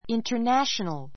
intə r nǽʃənl インタ ナ ショ ヌる